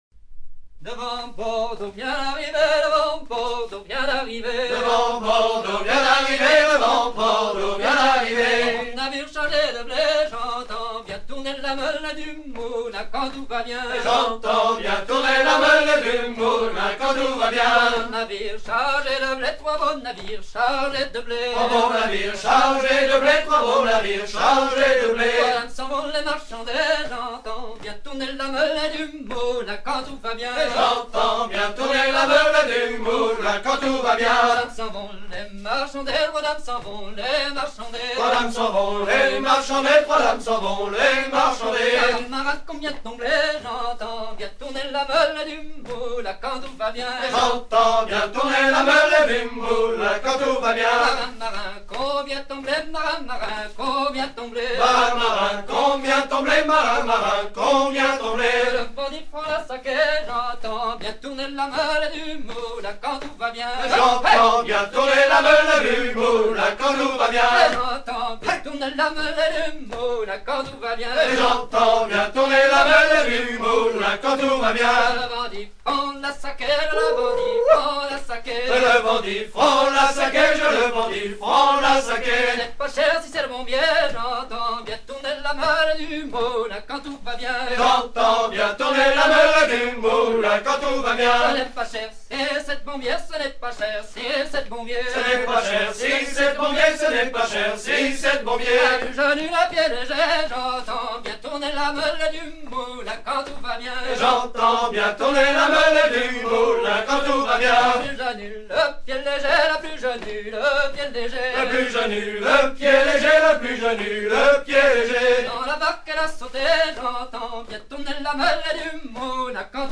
Version recueillie vers 1980 auprès de chanteuses de l'île d'Houat, le chant soutient la ridée
danse : ridée : ridée 6 temps